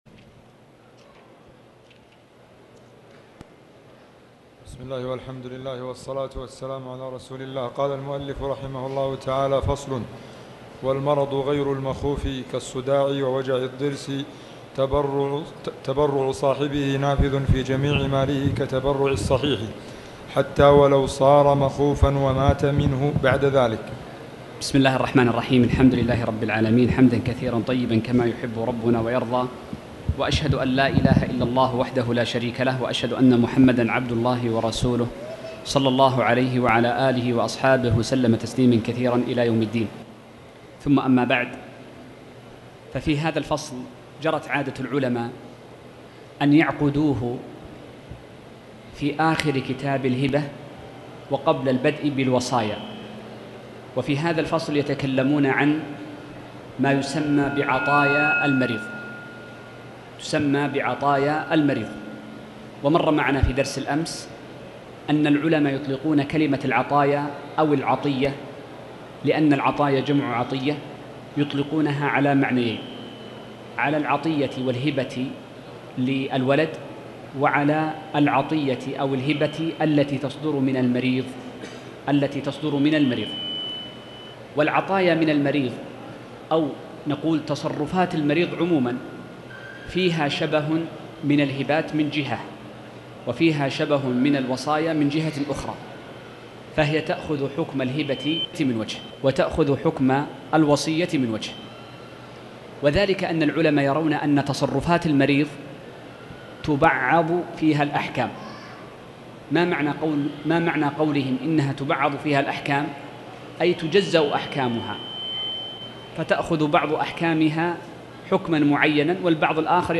تاريخ النشر ٢١ صفر ١٤٣٩ هـ المكان: المسجد الحرام الشيخ